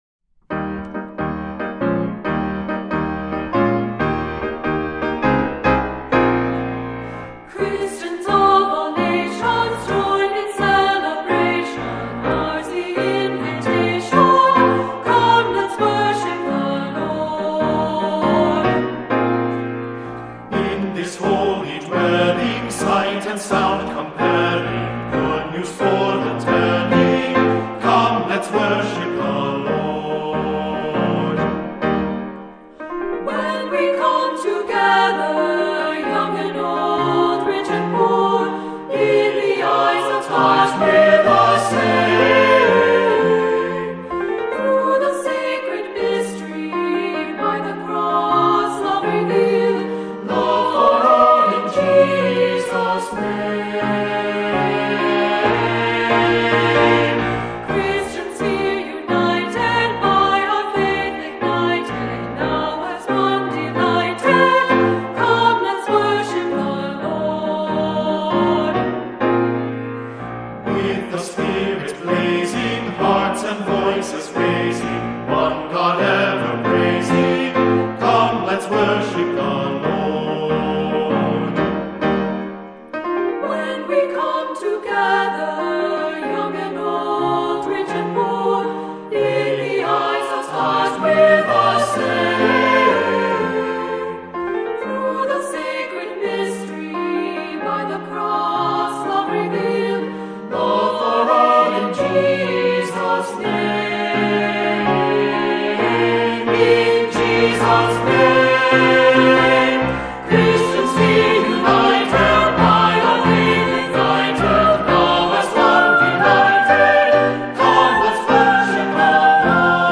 Voicing: Descant,SATB